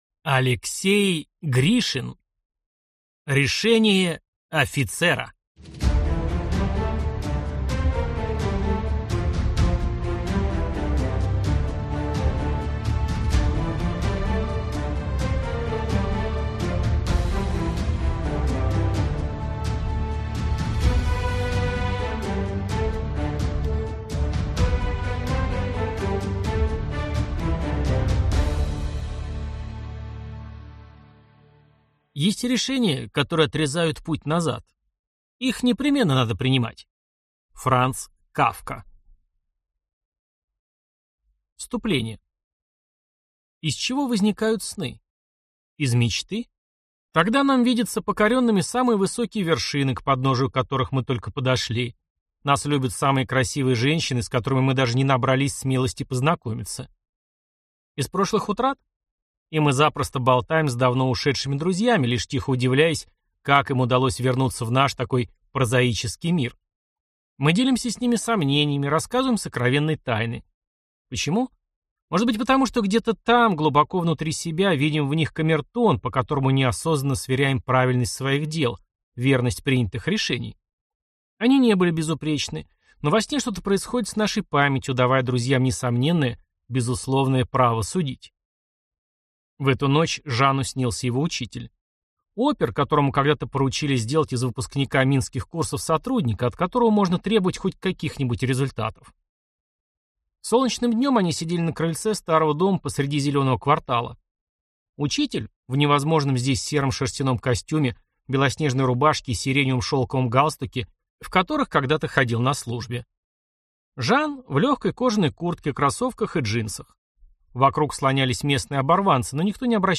Аудиокнига Решение офицера | Библиотека аудиокниг